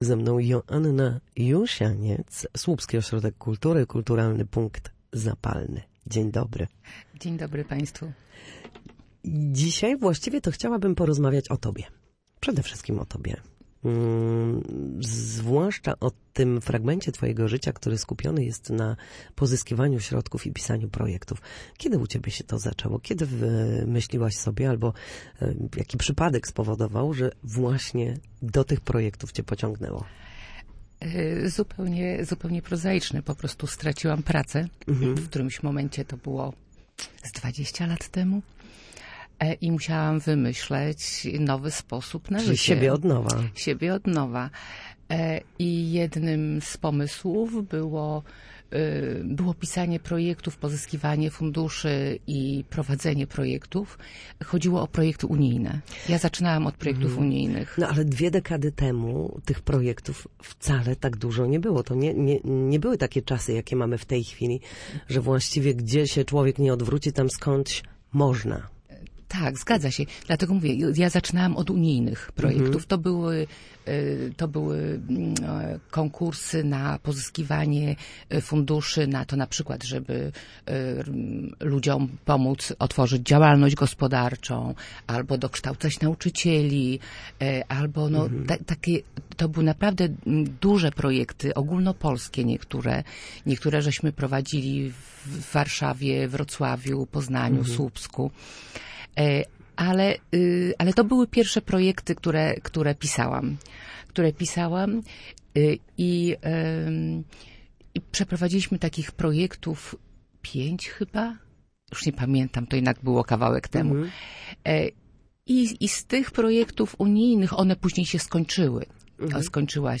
Na naszej antenie mówiła o pomysłach na szukanie możliwości, słuchaniu i umiejętności łączenia wizji